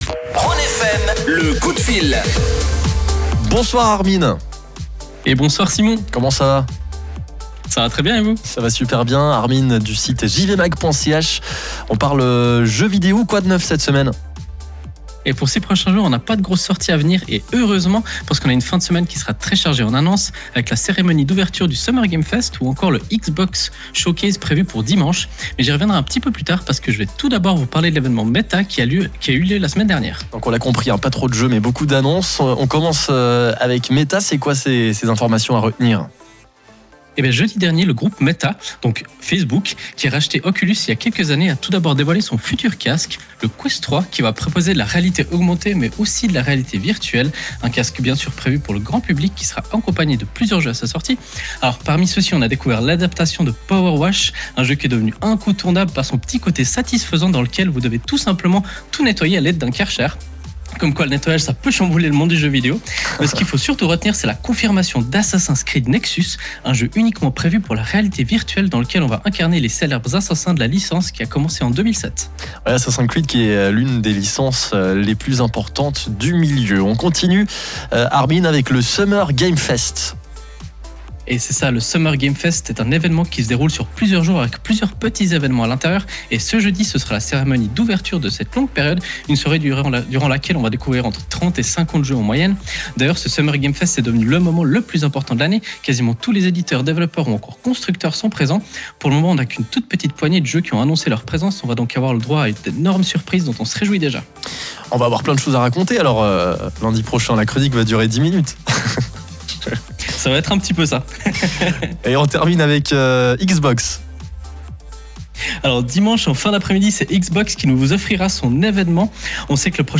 Le direct est à réécouter juste en dessus, comme d’habitude.